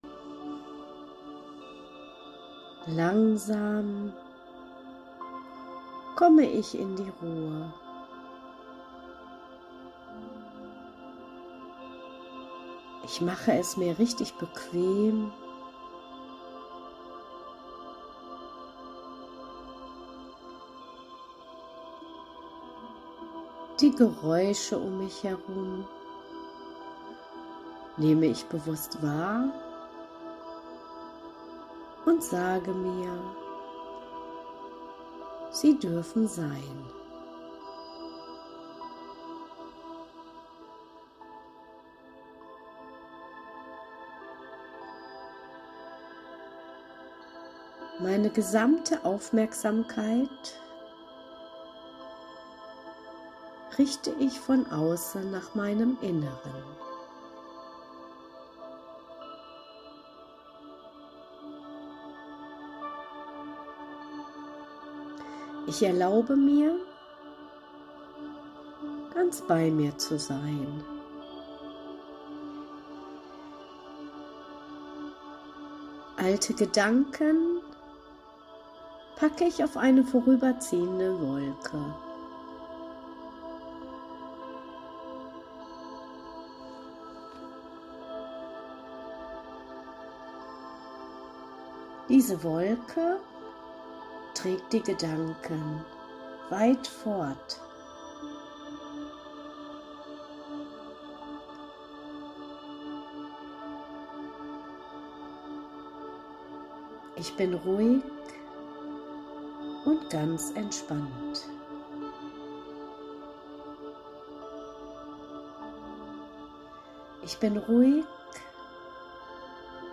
Kurzentspannung Autogenes Training